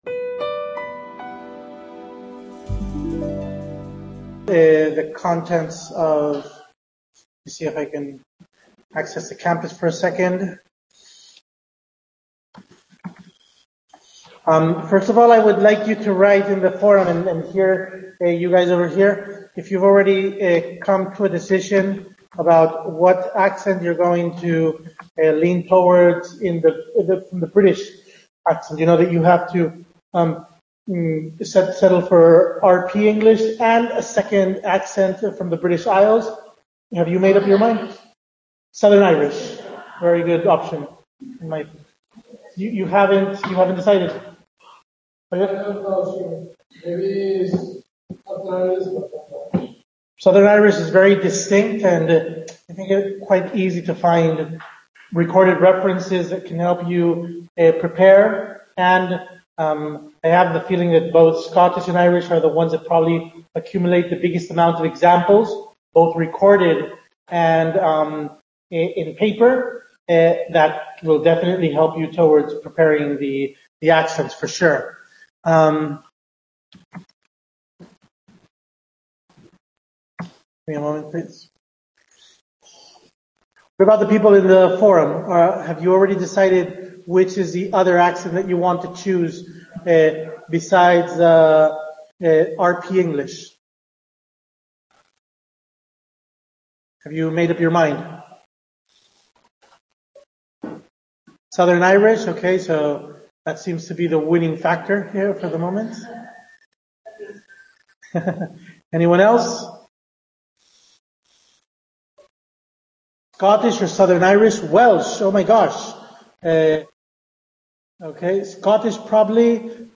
Webcoference celebrated at the Madrid Sur Center on March 5th 2020